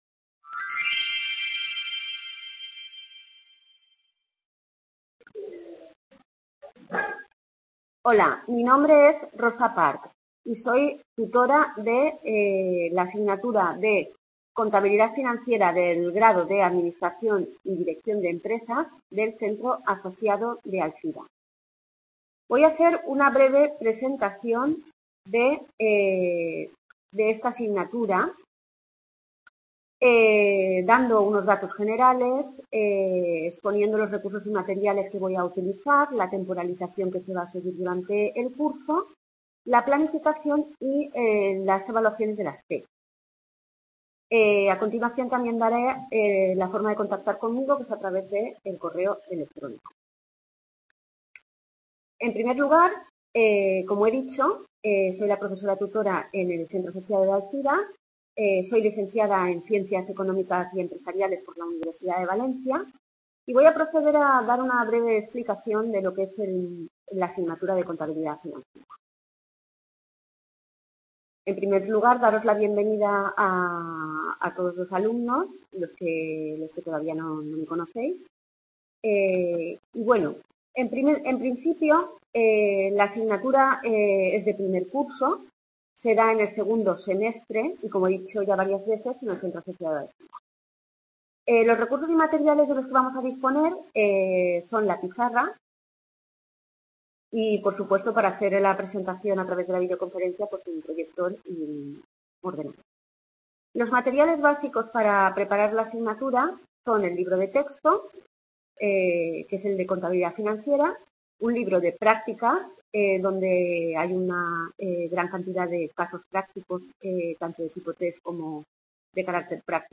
Video Clase